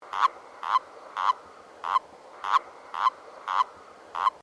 Erdkröte
Diese Tonspur zeigt die typischen Laute der Erdkröte.
Erdkroete.mp3